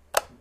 switch21.wav